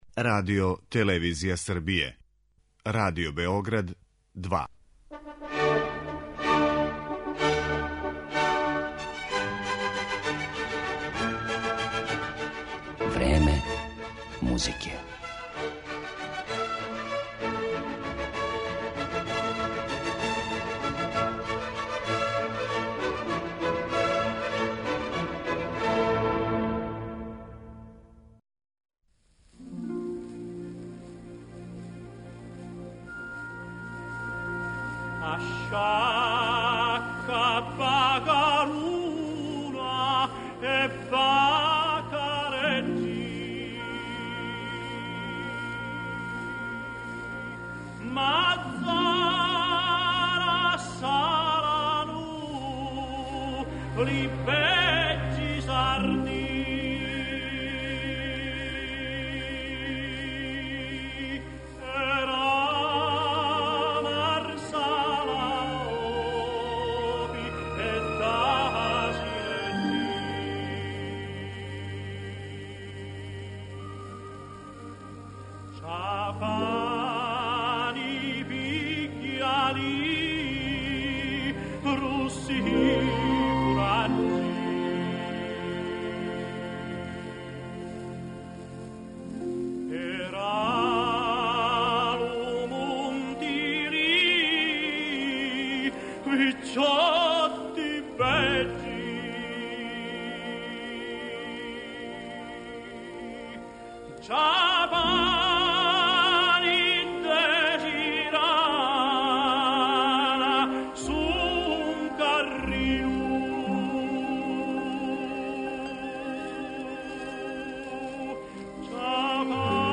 'Певати само музику није добро. Морају се певати речи и то из срца!' - изјавио је једном приликом Ђузепе ди Стефано, славни италијански тенор, коме је посвећена данашња емисија Време музике.
Портрет овог уметника осликаће арије из најпознатијих опера Пучинија, Вердија и Маснеа.